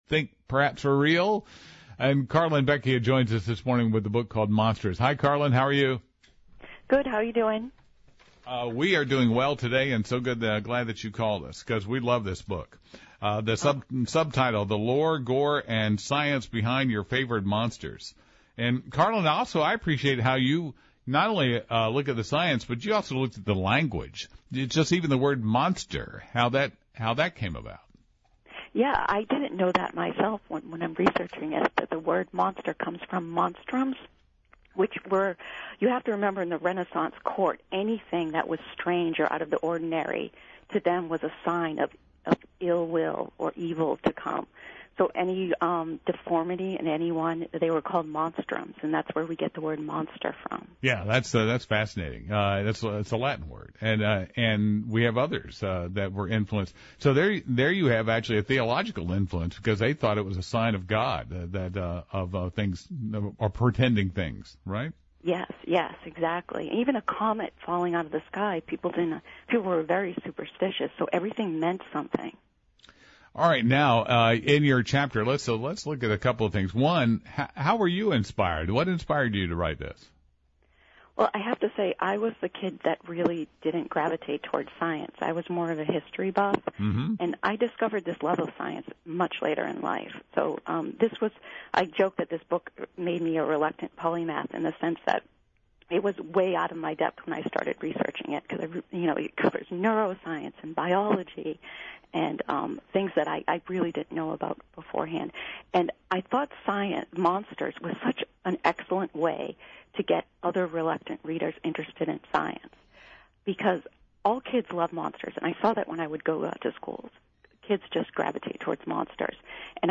Recent Radio Interviews
MonstrousInterview.mp3